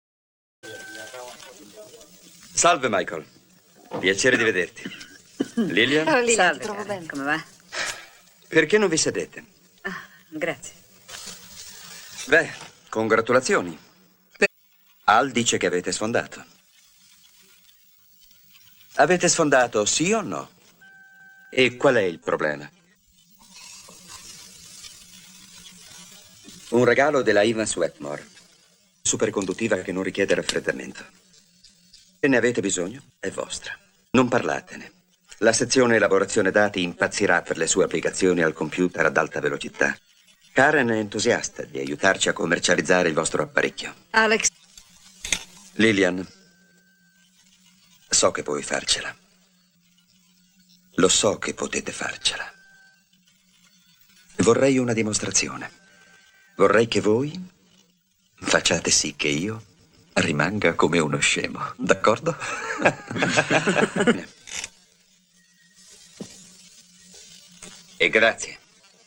nel film "Brainstorm - Generazione elettronica", in cui doppia Cliff Robertson.